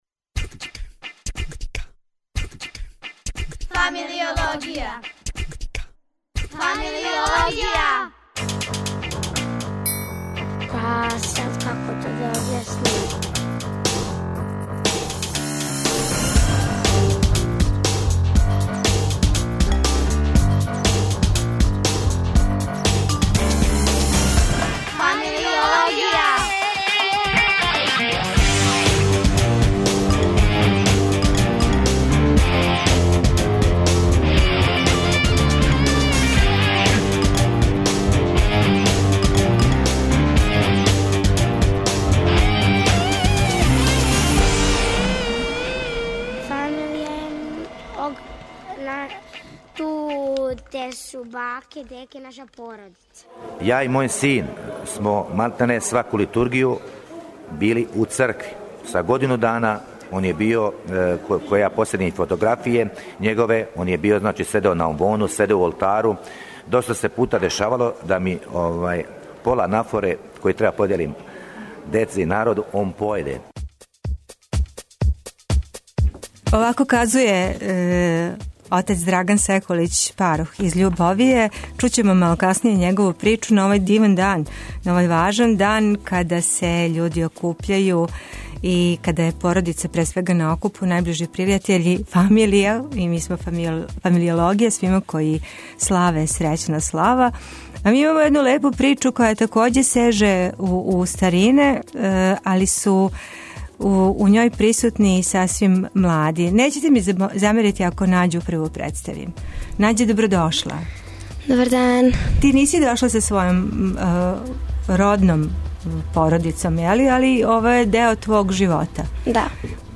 Радио Београд 1, 13.00